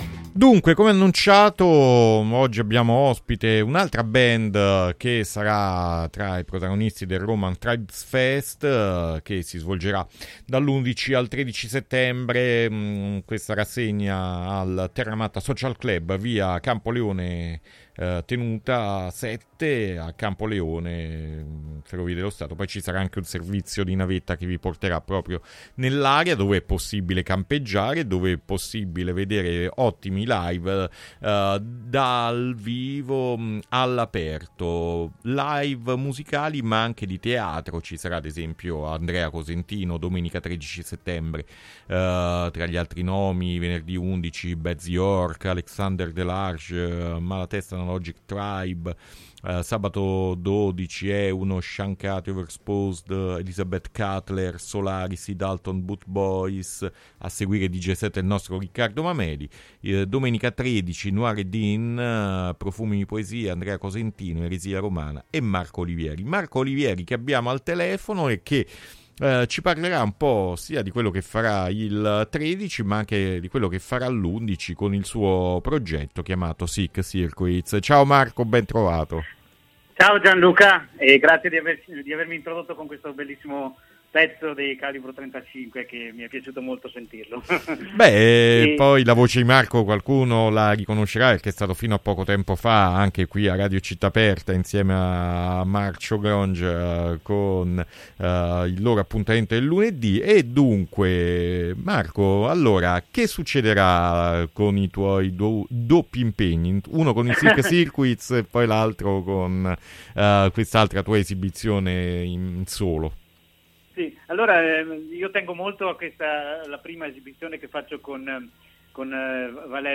Intervista Sick Circuits per Roman Tribes Fest | Radio Città Aperta